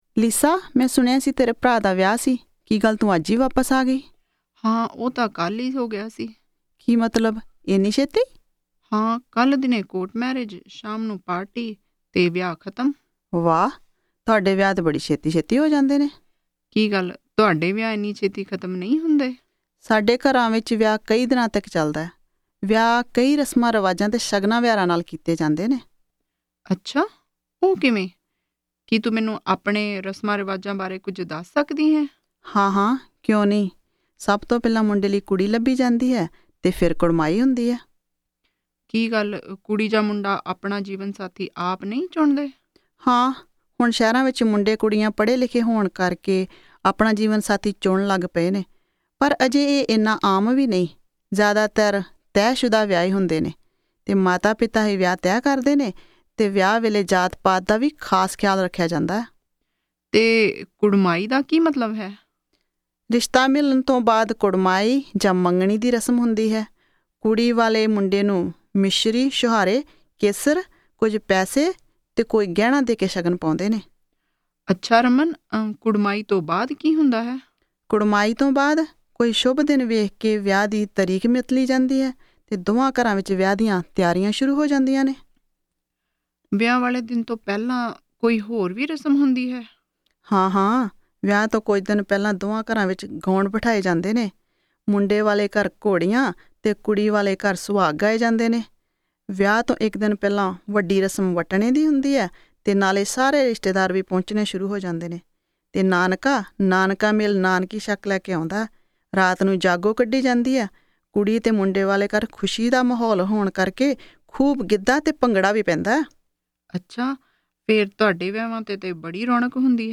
Punjabi Conversation 16 Listen